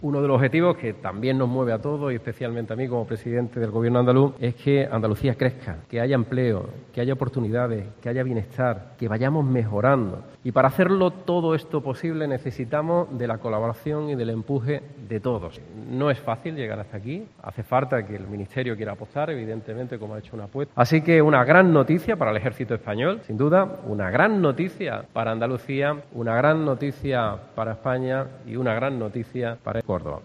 Escucha a Juanma Moreno, presidente de la Junta de Andalucía